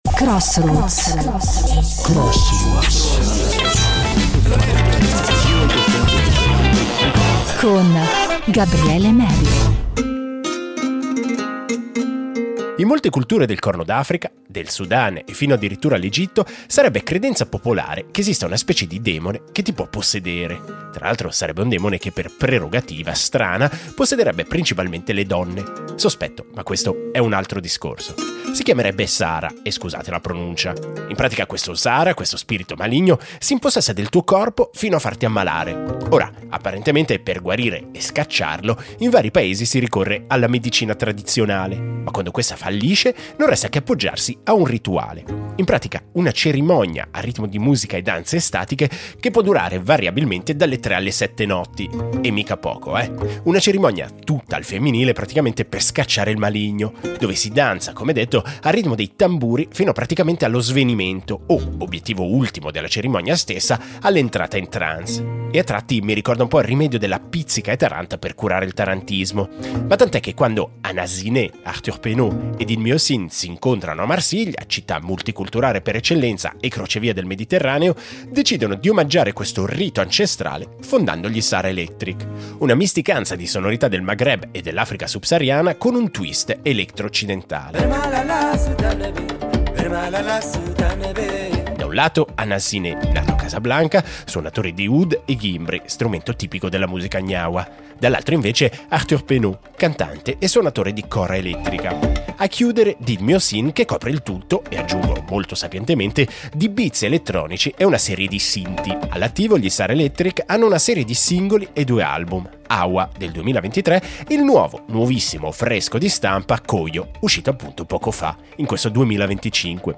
musica che cura, ipnotizza e porta alla trance.